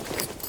Gear Rustle Redone
tac_gear_30.ogg